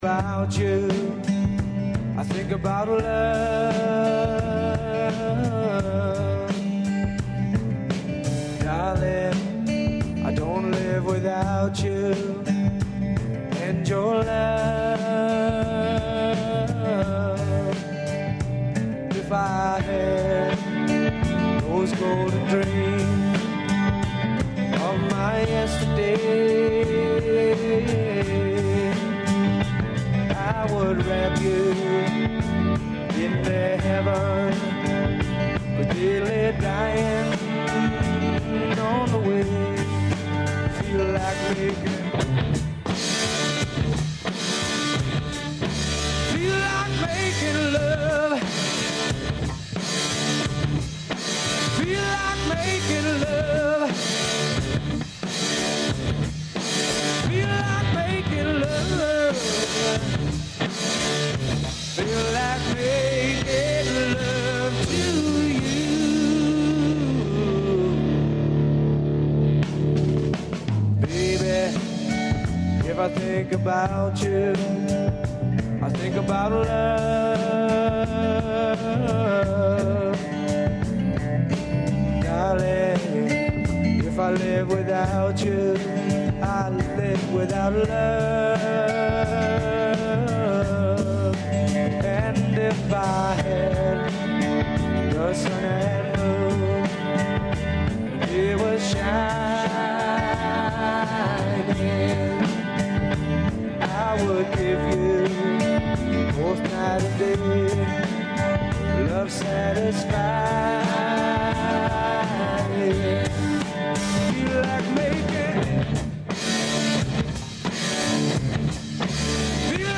Уз Хит 202 су одрастале рокенрол генерације и афирмисали су се највећи домаћи бендови.